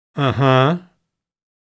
Uh-huh-sound-effect.mp3